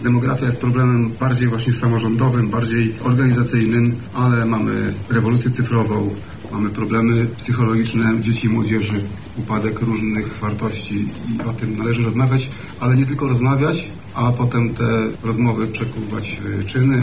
Dzisiaj (18 marca) odbyła się 13. edycja Ogólnopolskiej Konferencji Samorządu i Oświaty ,,Edukacja Przyszłości”.
O głównych problemach mówi Tomasz Szabłowski, Kurator Oświaty w Mieście Lublin: